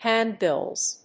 発音記号
• / ˈhæˌndbɪlz(米国英語)